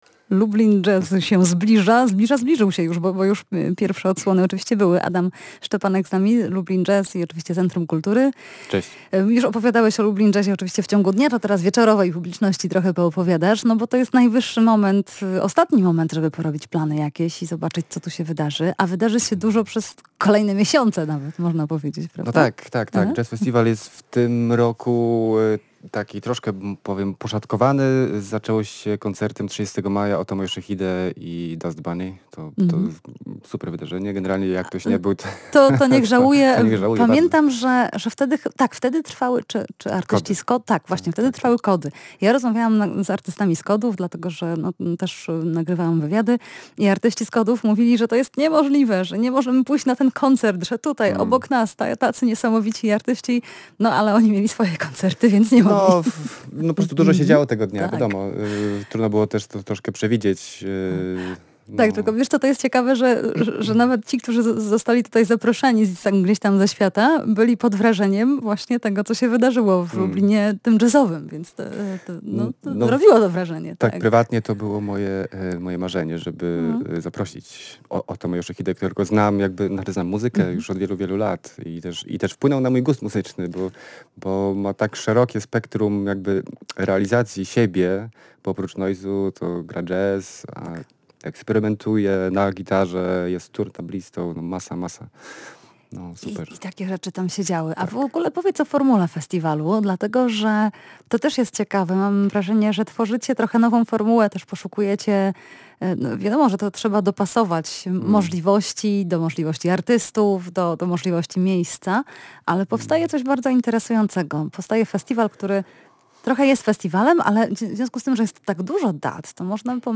Jazz a vu: Lublin Jazz Festival [POSŁUCHAJ ROZMOWY]